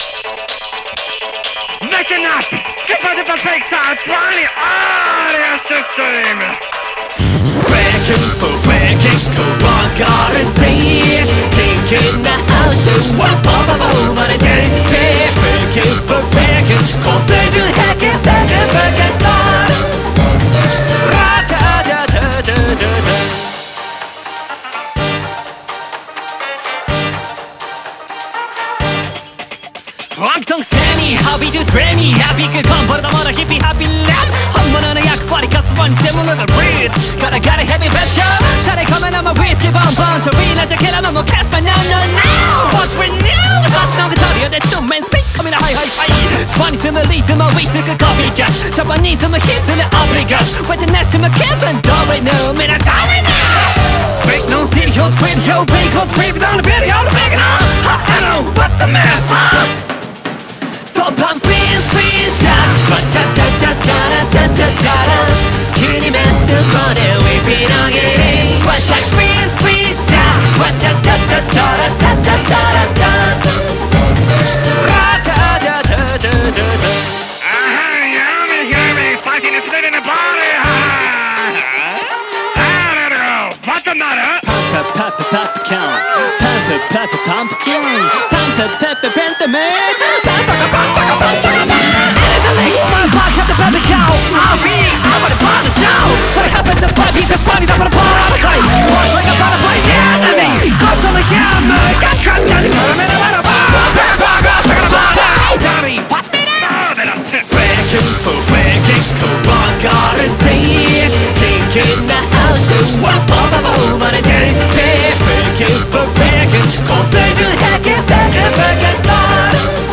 BPM125
MP3 QualityMusic Cut